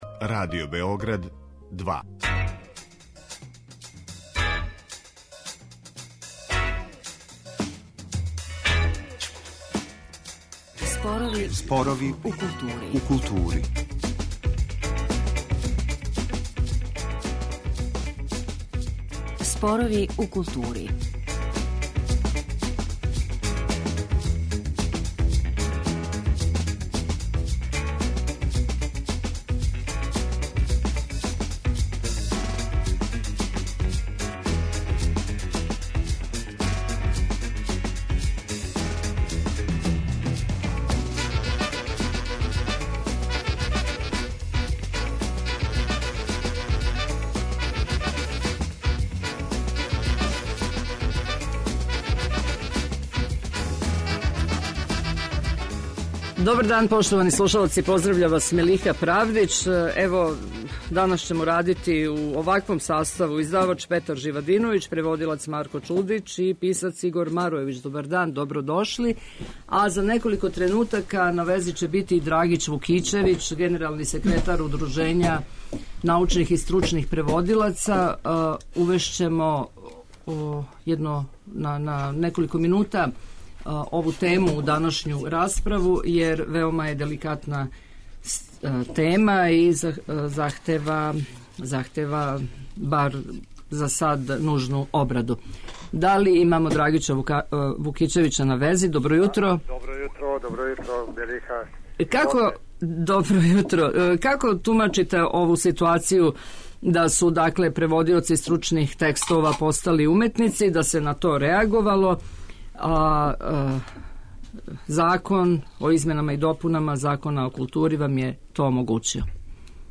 Зашто ова област није још увек регулисана Законом о издаваштву, основно је и полазишно питање за данашњи разговор.